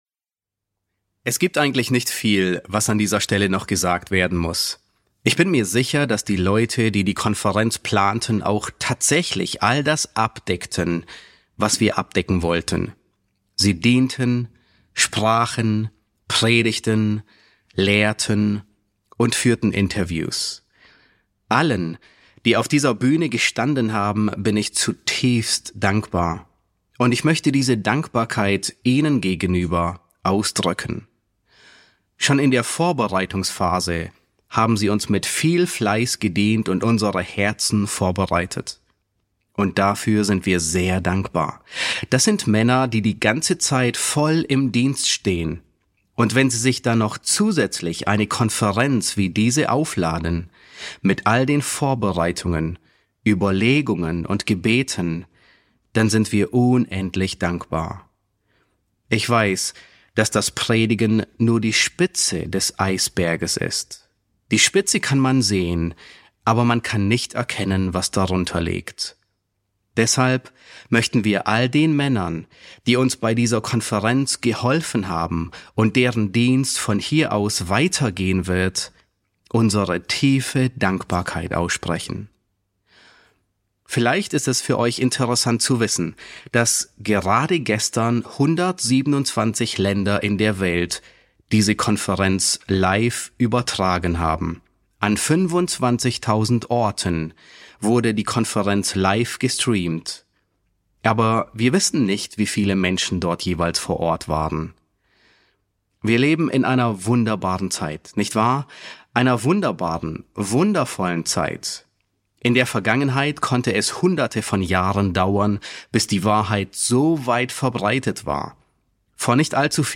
Predigten auf Deutsch